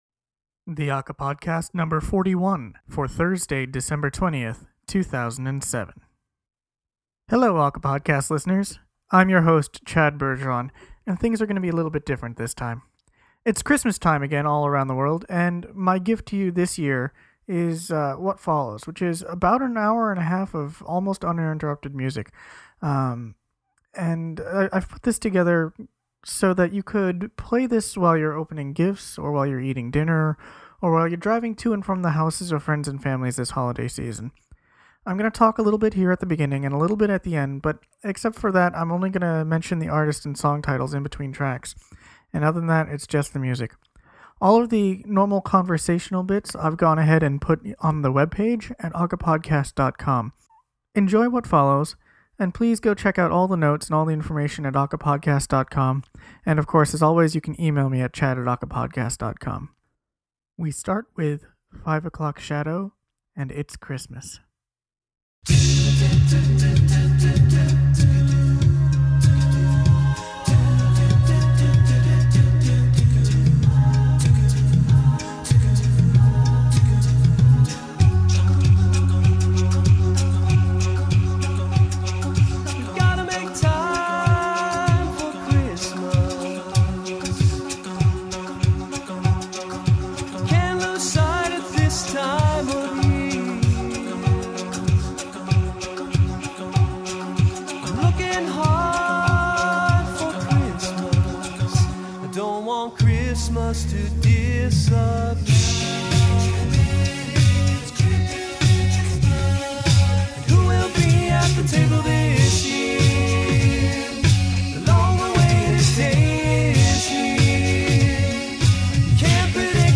rocking vocal originals